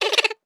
ihob/Assets/Extensions/CartoonGamesSoundEffects/Laugh_v1/Laugh_v3_wav.wav